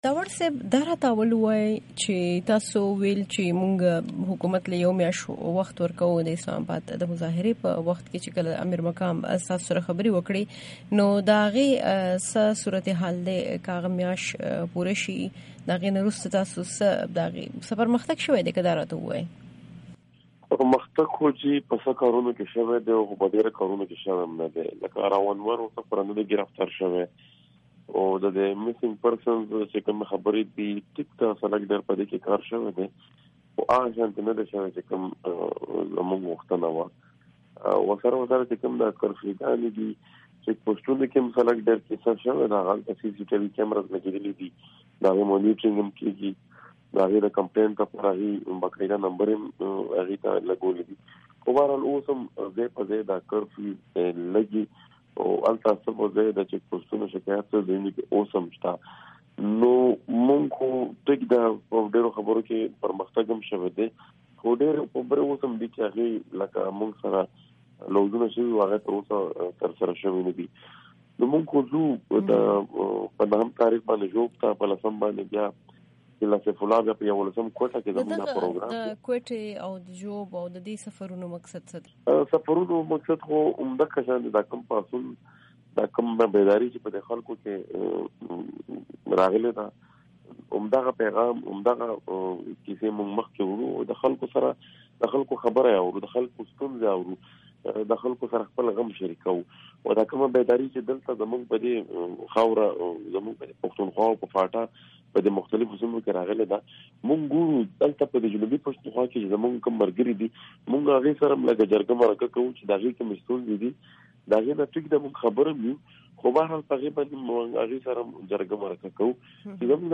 د محسن داوړ سره د پښتون تحفظ تحریک په اړه مرکه